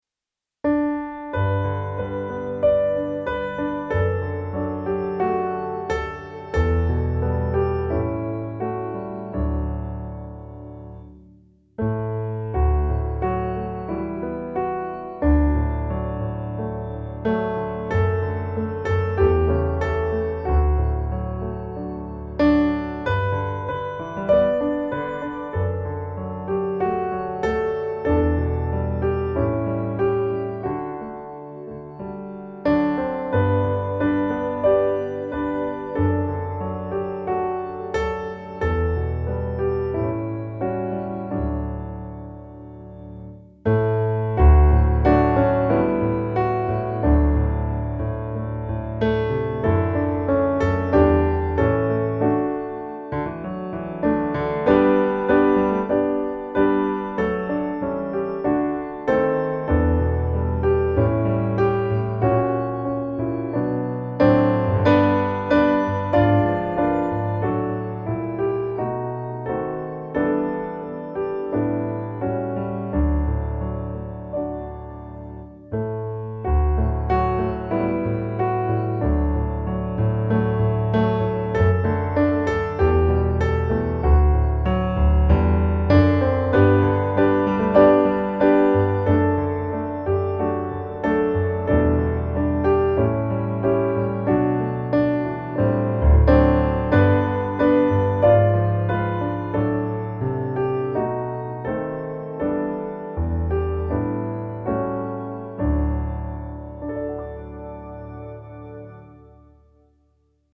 När juldagsmorgon glimmar - musikbakgrund
Musikbakgrund Psalm